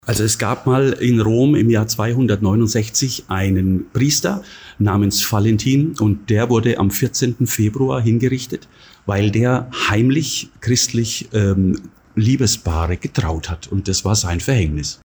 Interview: Warum feiern wir Valentinstag?